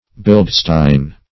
Search Result for " bildstein" : The Collaborative International Dictionary of English v.0.48: Bildstein \Bild"stein\, n. [G., fr. bild image, likeness + stein stone.]